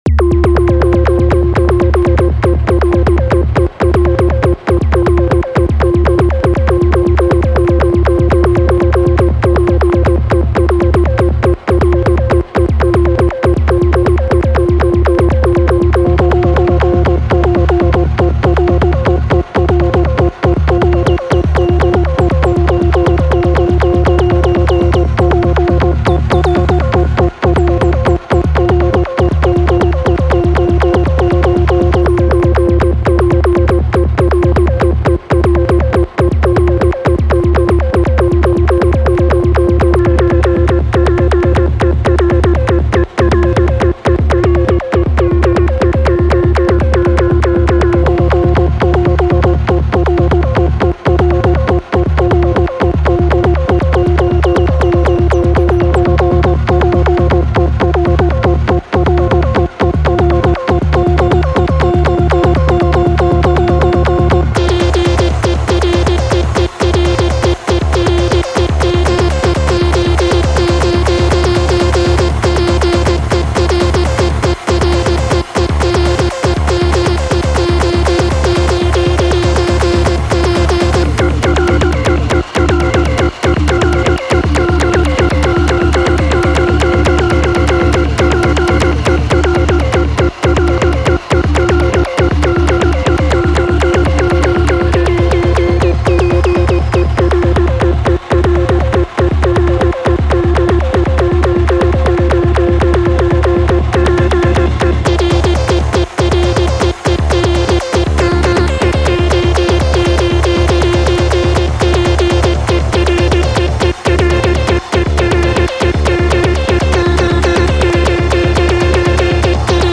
Got Fors Kit. Fun stuff :slight_smile: